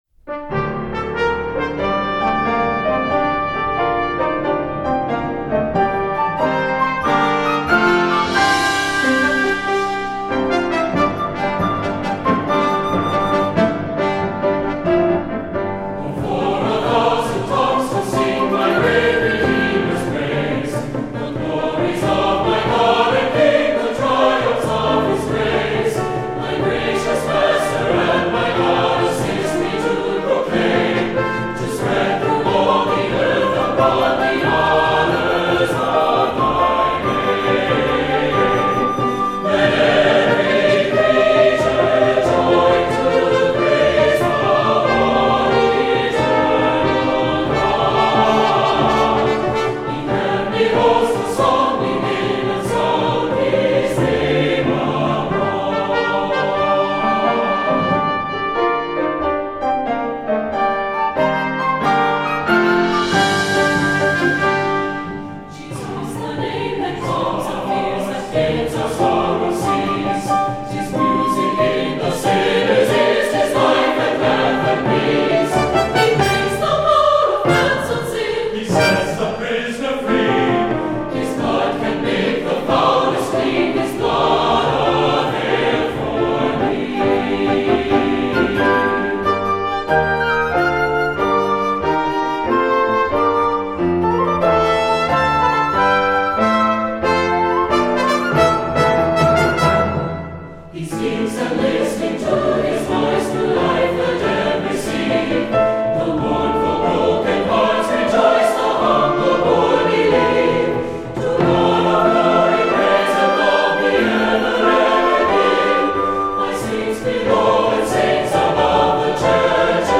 Voicing: SATB divisi and Piano